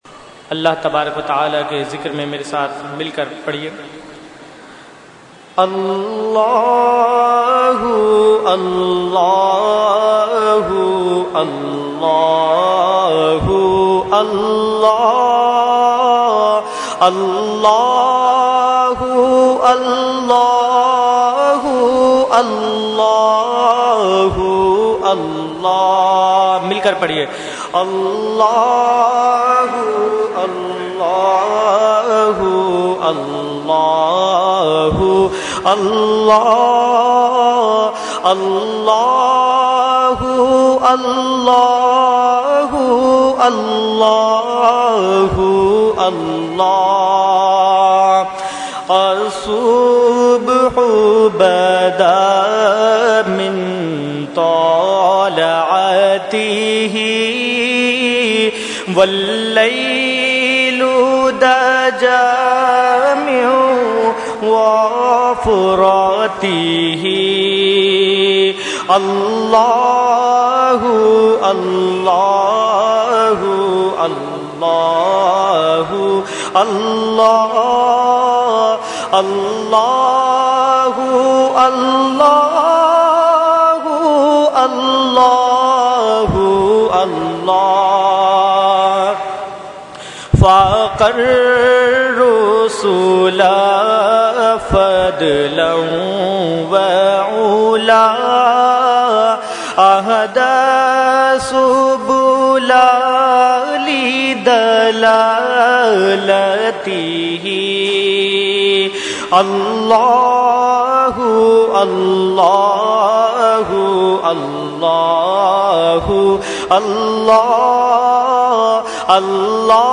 Category : Hamd | Language : UrduEvent : Dars Quran Farooqi Masjid 8 June 2012